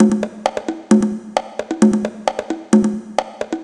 132_conga_1.wav